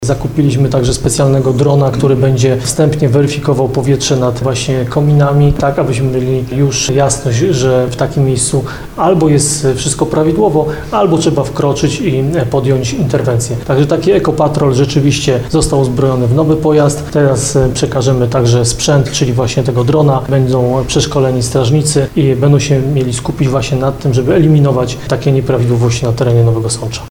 – mówi Ludomir Handzel, prezydent Nowego Sącza.